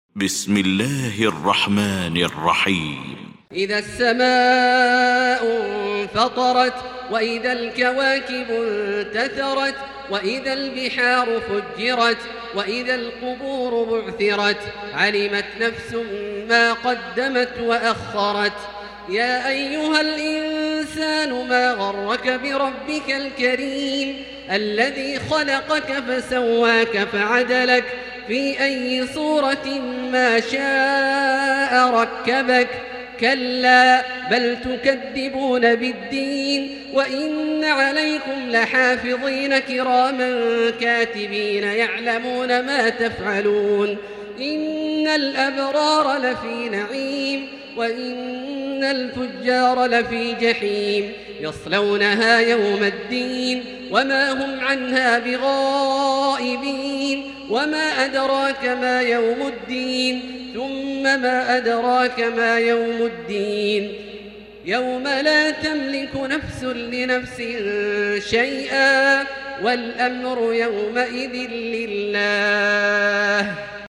المكان: المسجد الحرام الشيخ: فضيلة الشيخ عبدالله الجهني فضيلة الشيخ عبدالله الجهني الانفطار